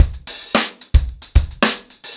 TupidCow-110BPM.47.wav